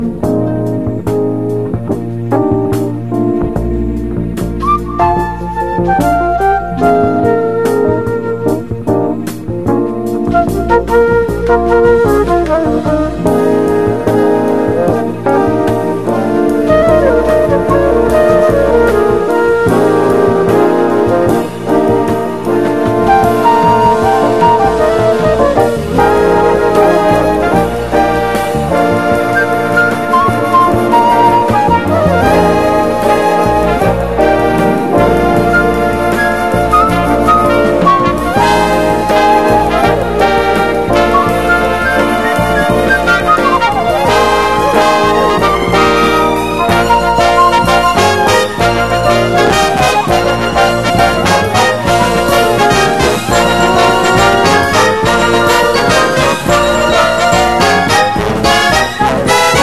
JAZZ / DANCEFLOOR / JAZZ FUNK / DRUM BREAK
アブストラクトなジャズ・ファンク！
タイトなドラムにベースがブリッと絡む、引き締まったジャズ・ファンク